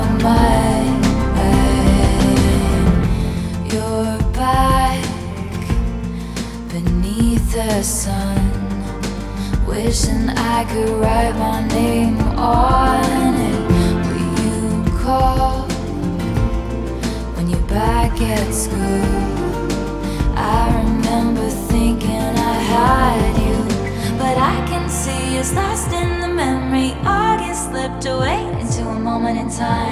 • Alternative